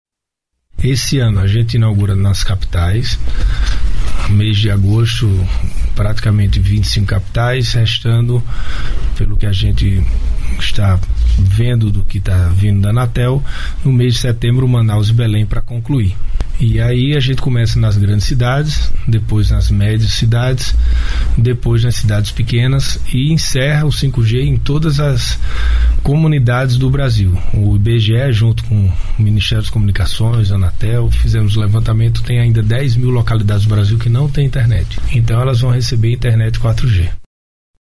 Sonora-Fabio-Farias-ministro-das-Comunicacoes.mp3